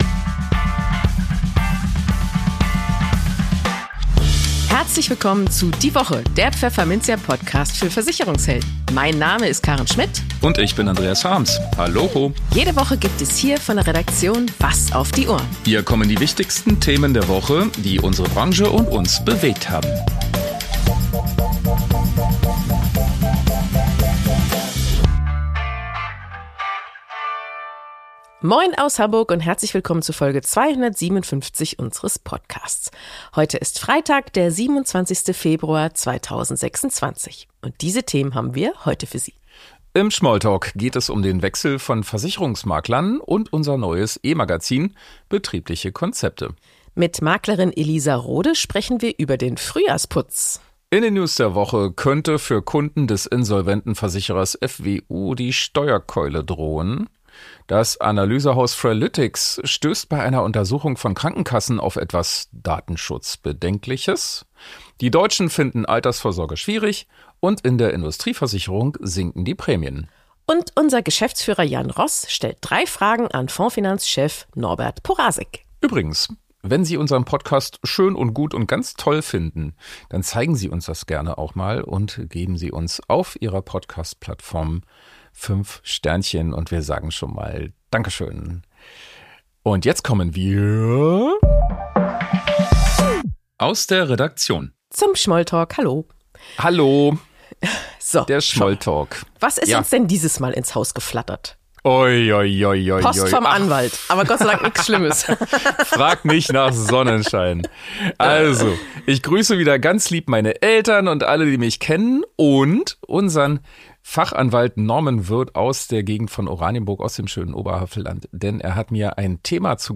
Warum und wie sie das getan hat, erklärt sie im Gespräch. Ab 00:21:42: In den News der Woche könnte für Kunden des insolventen Versicherers FWU die Steuerkeule drohen.